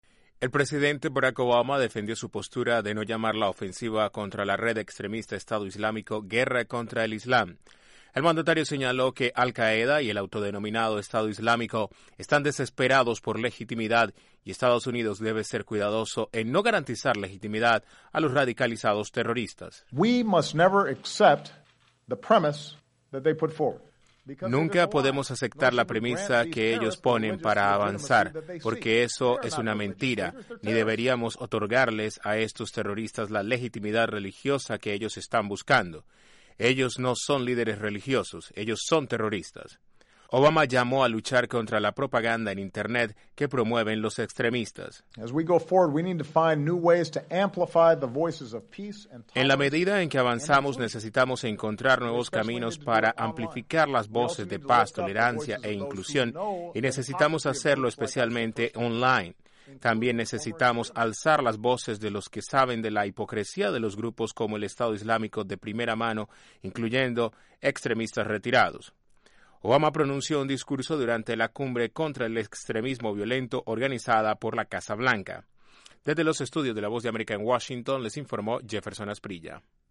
En un discurso durante la Cumbre contra el Extremismo Violento en la Casa Blanca, el presidente Obama dijo que EE.UU. está en guerra con quienes han pervertido al Islam.